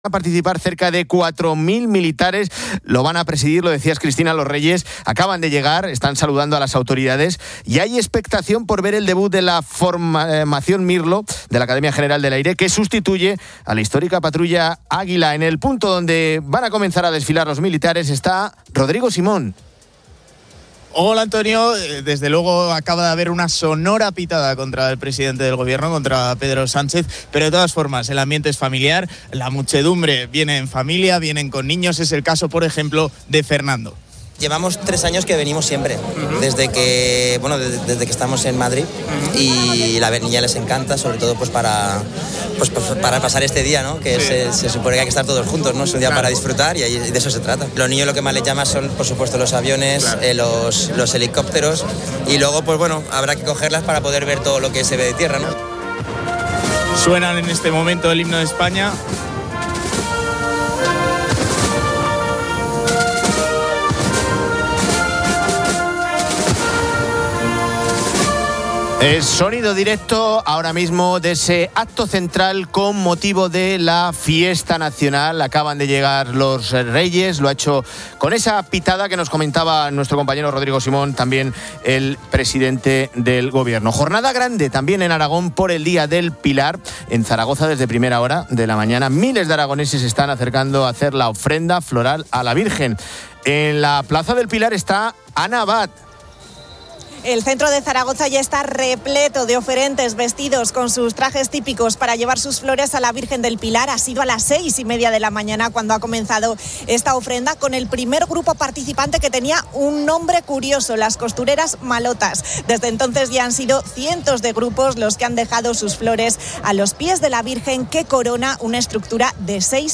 desde las calles de Madrid con motivo del día de la Hispanidad.